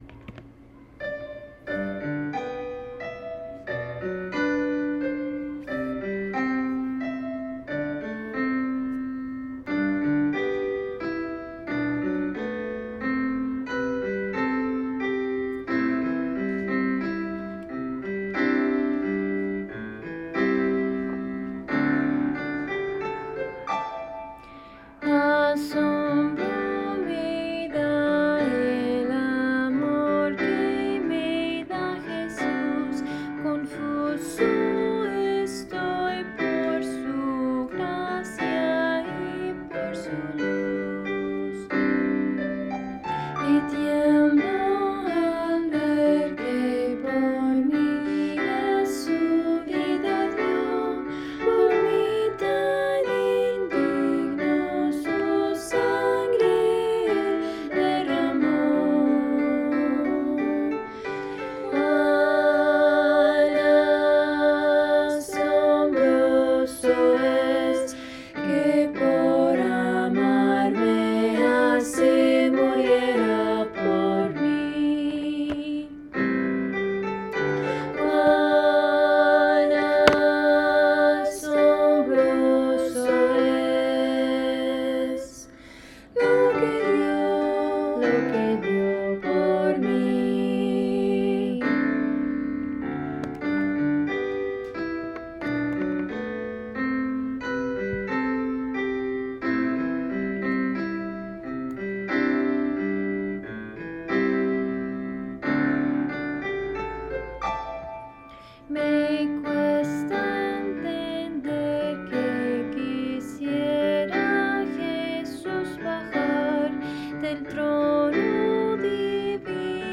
Voicing/Instrumentation: SAT